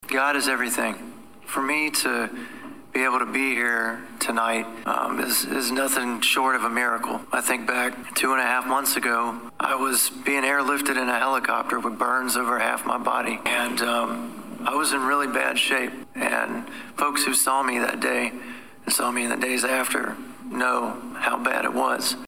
Just under three months after he was attacked and set on fire Danville City Councilman Lee Vogler attended his first city council meeting since the attack tonight.
During the meeting Vogler spoke about his condition after the attack.